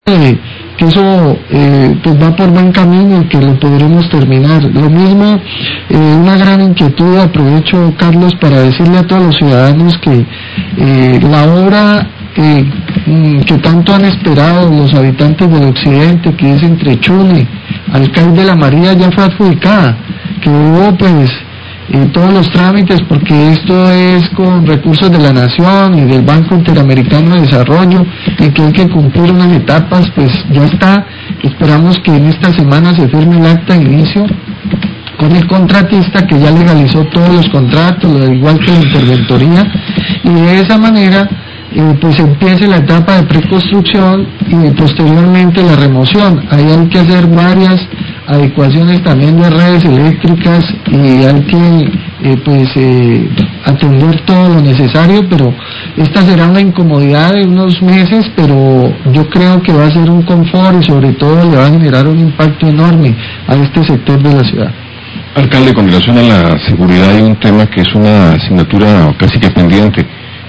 Radio
Declaraciones del Alcalde de Popayán, Cesar Cristian Gómez.